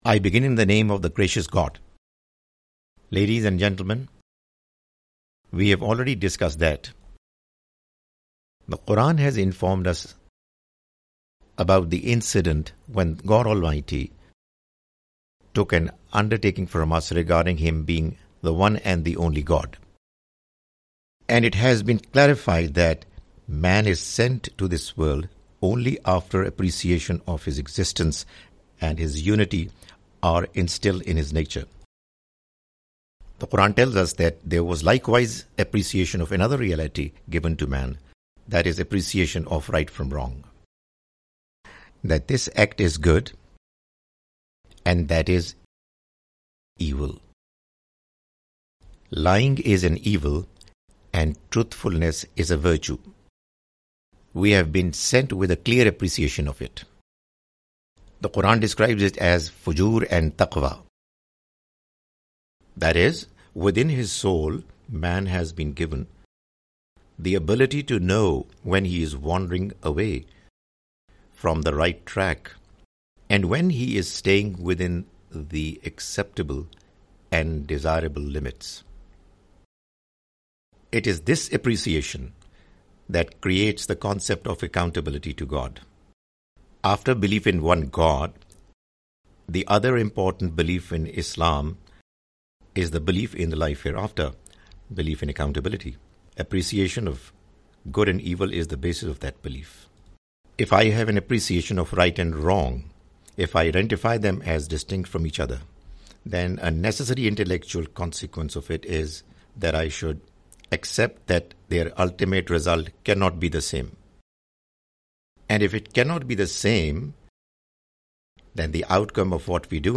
The Message of Qur'an (With English Voice Over) Part-2
The Message of the Quran is a lecture series comprising Urdu lectures of Mr Javed Ahmad Ghamidi.